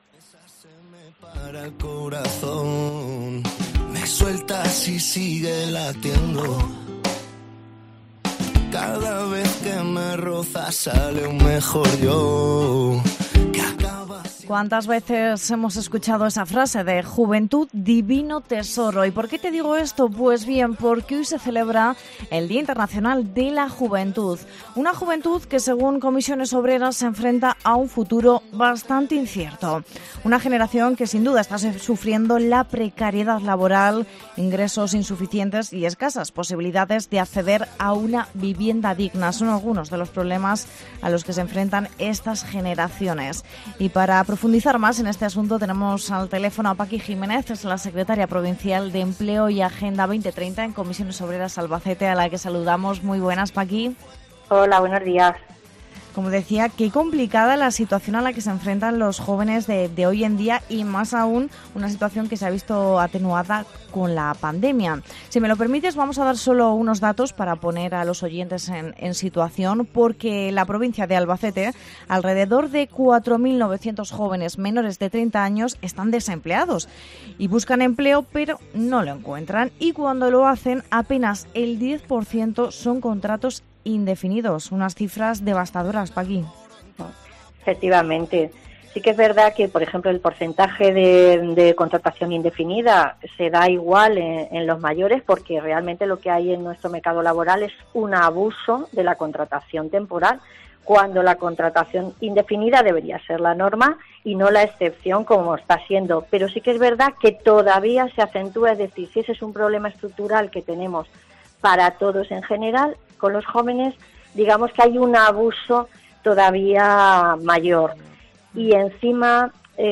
ctv-diz-entrevista-dia-de-la-juventud